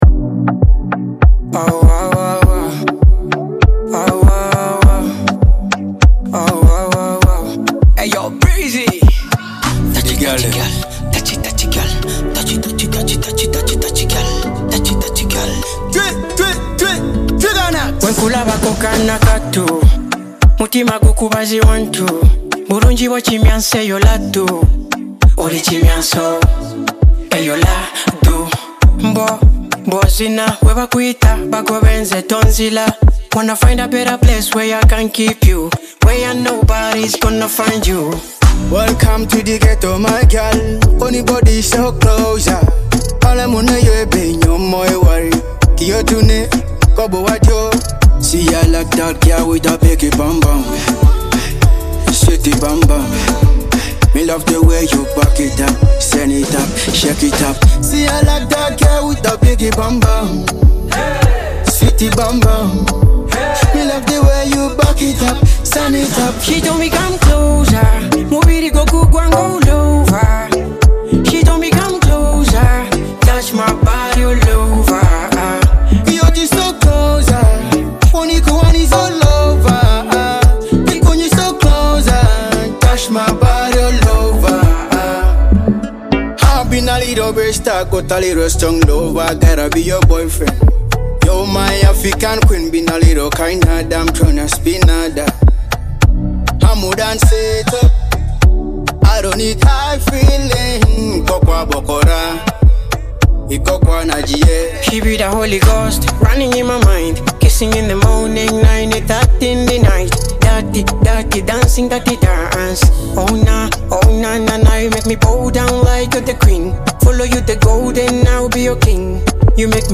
An energetic remix that will keep you dancing all day!